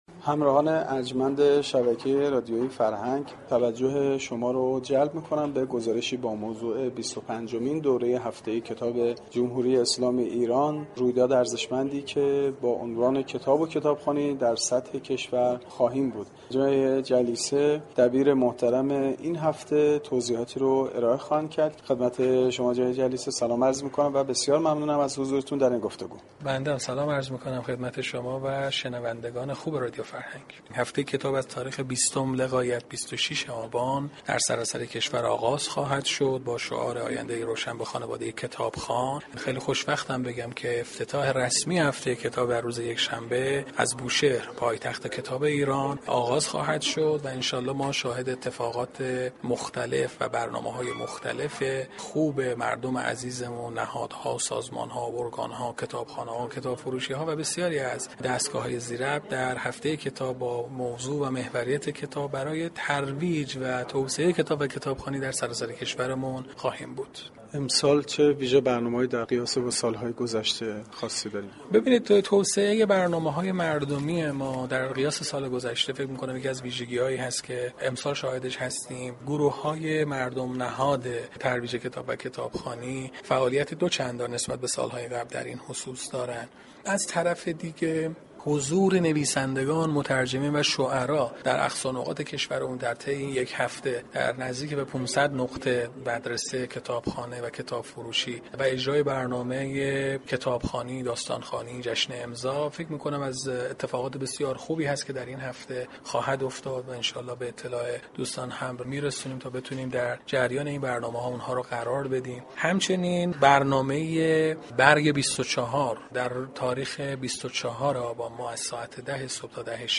در گفتگوی اختصاصی با گزارشگر رادیو فرهنگ درباره ی برگزاری این هفته گفت